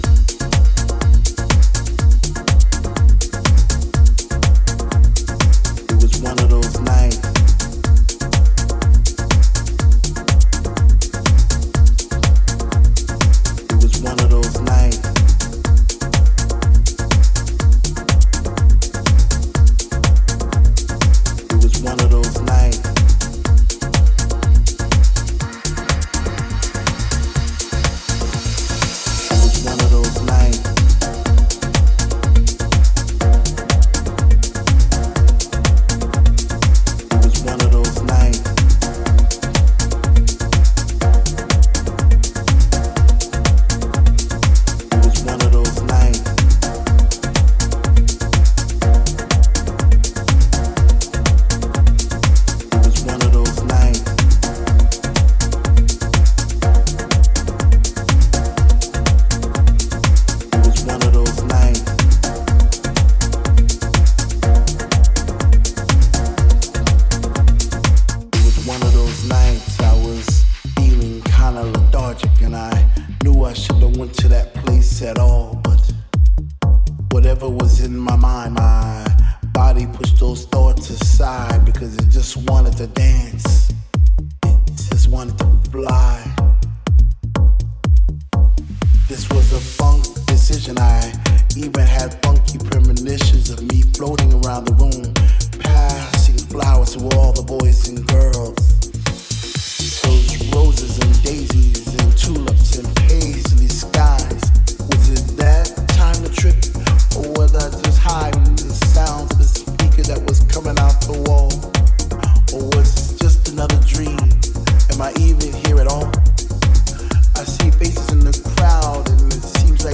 ピークに向かって淡々とビルドアップするトラックに、有機的な奥行きとソウルフルネスを加えたB-2が特におすすめです！